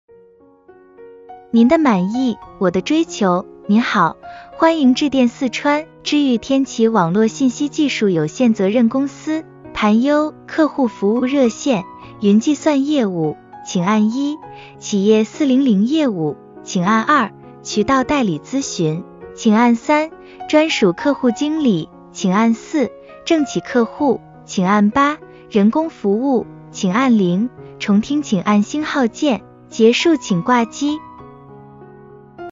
企业400电话IVR导航按键彩铃试听（案例）
IVR音频录制试听案例：